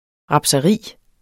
Udtale [ ˈʁɑbsʌˈʁiˀ ]